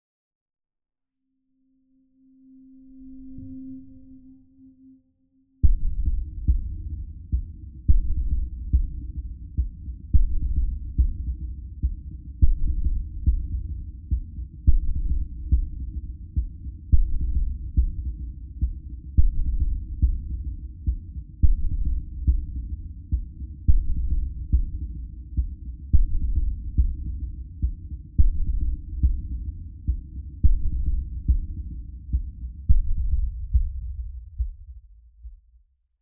STest1_200Hz.wav